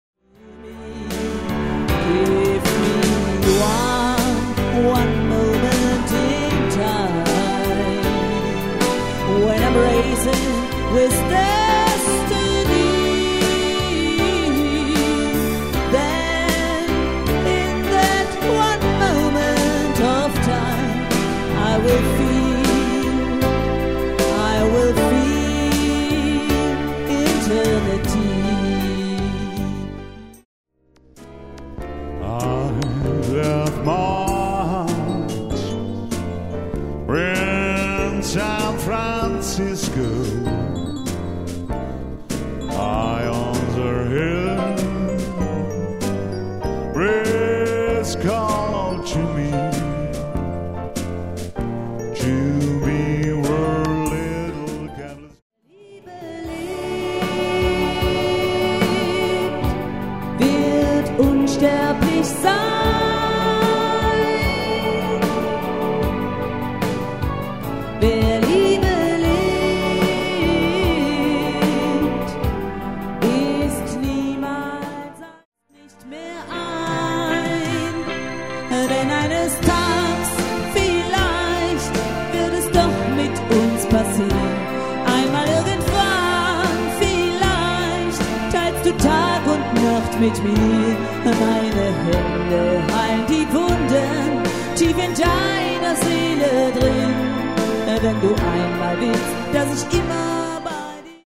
• Musikmix